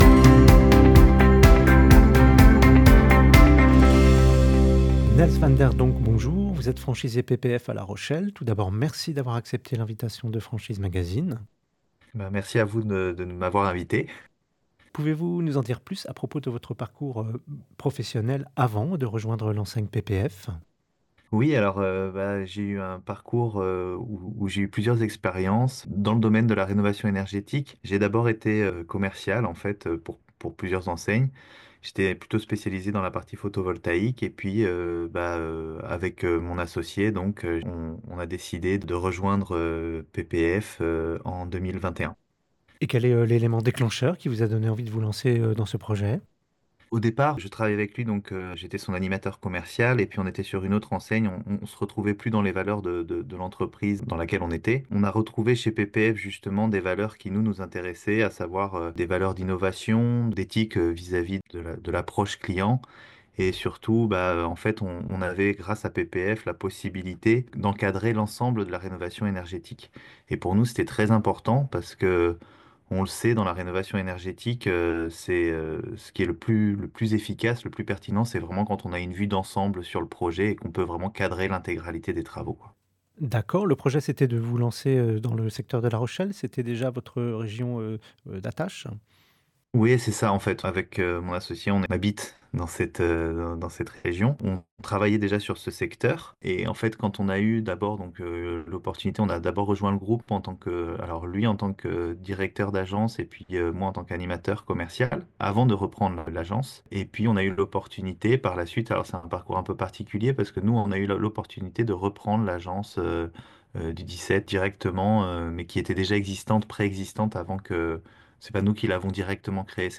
Au micro du podcast Franchise Magazine : la Franchise PPF - Écoutez l'interview